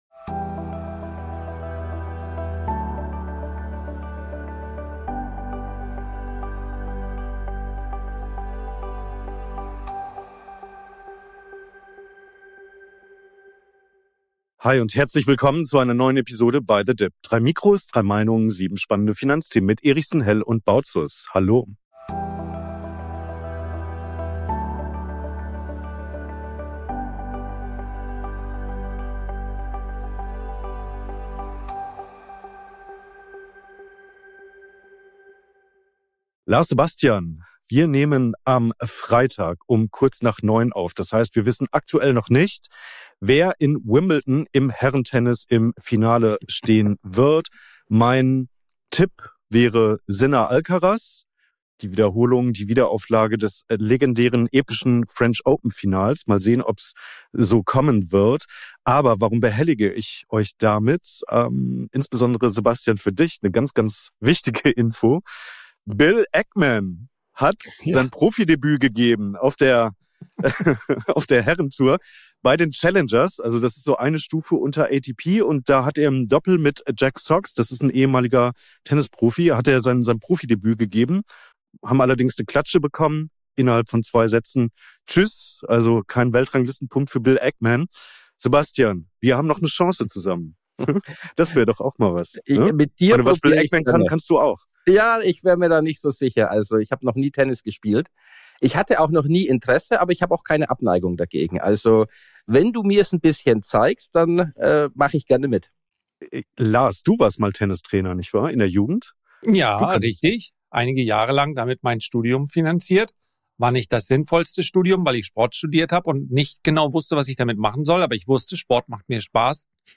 3 Mikrofone, 3 Meinungen